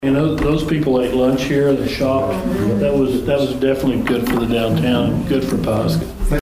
Mayor Steve Tolson said